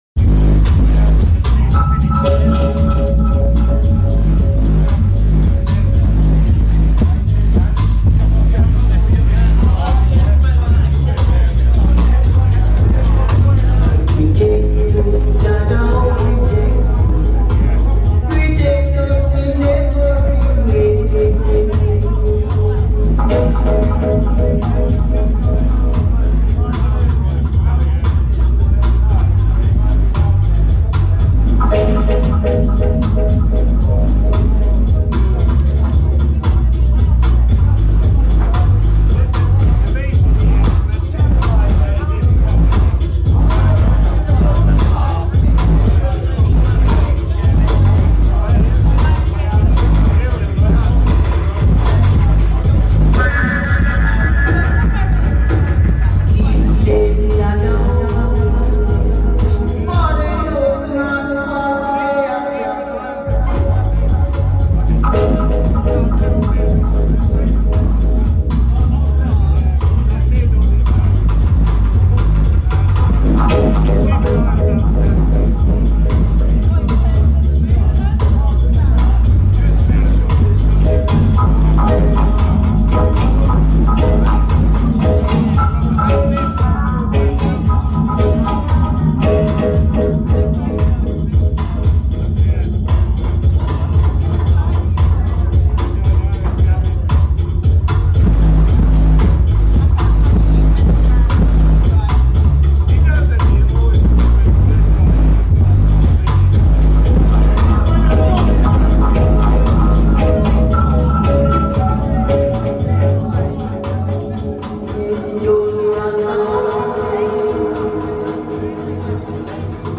Full sound, Dryad works, Sheffield
Live session